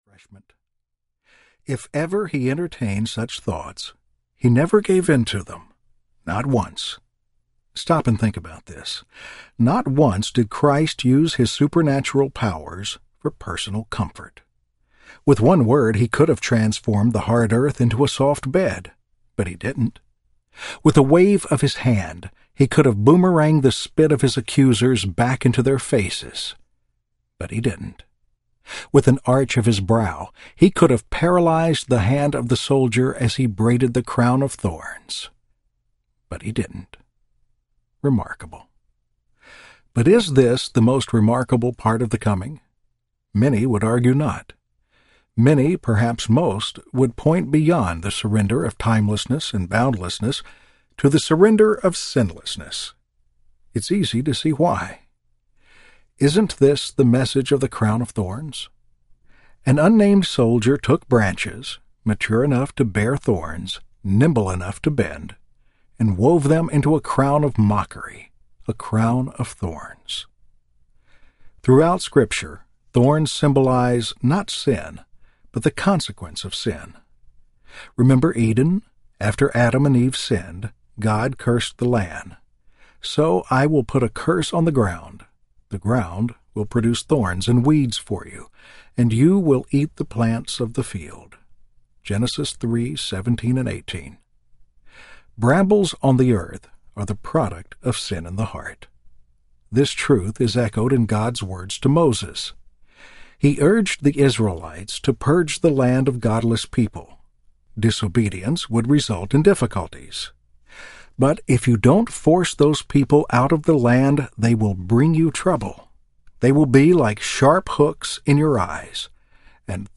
He Chose the Nails Audiobook
3.6 Hrs. – Unabridged